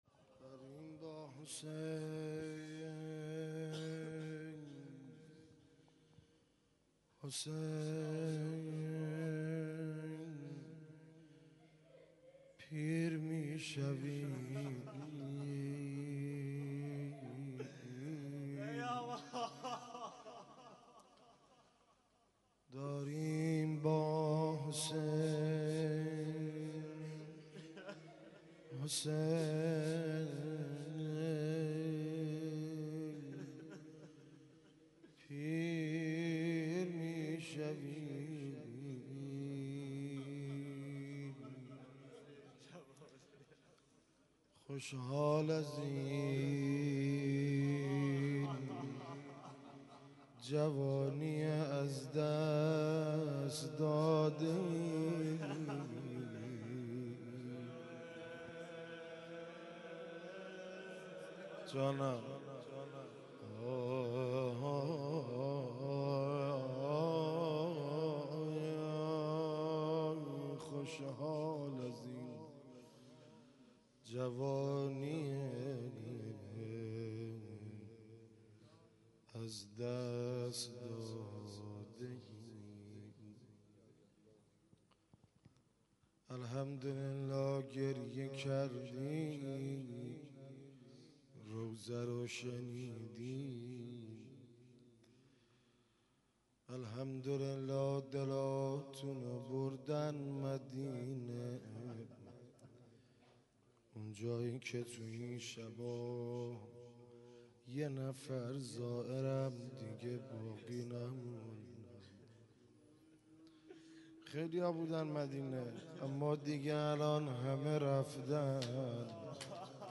2- روضه دوم